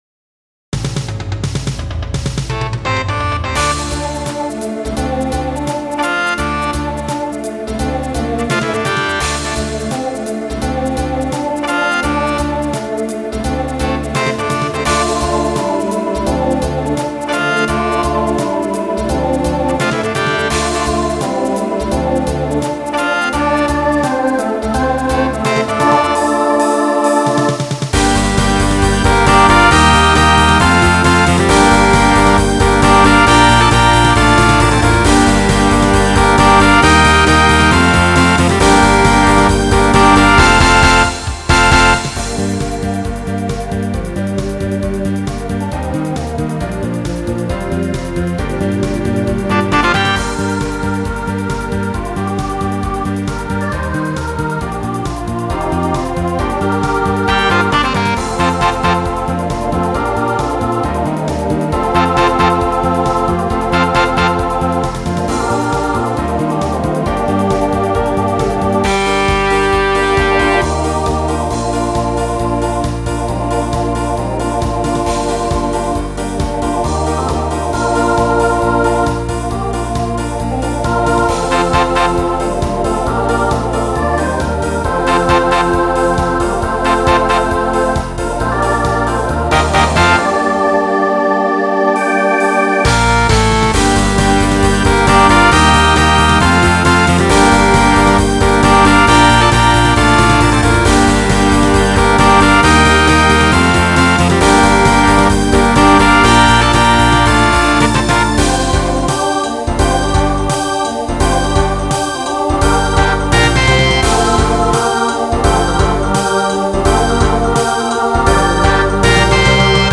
Voicing SATB Instrumental combo Genre Folk , Rock